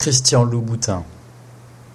Hit Play to hear the pronounciation christianlouboutin